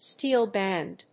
アクセントstéel bànd